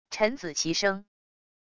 臣子齐声wav音频